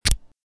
autotuer.mp3